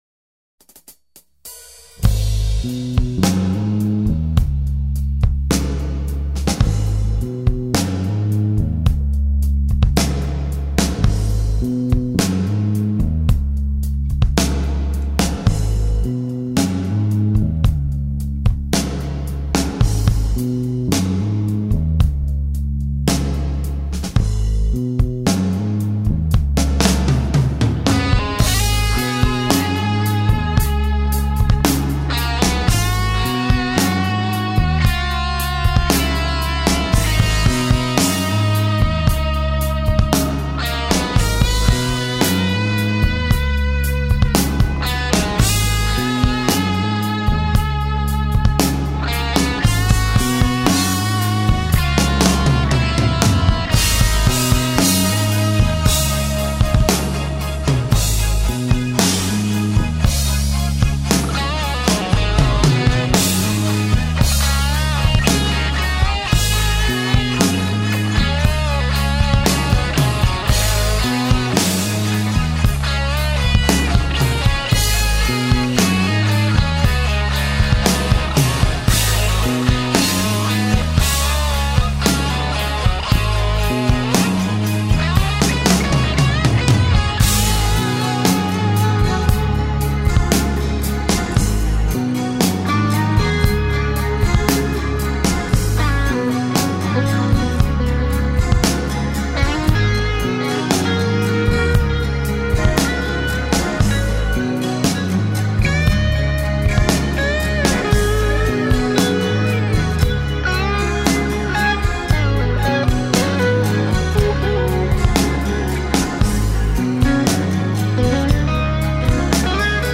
Experimental improvisation band.
all guitars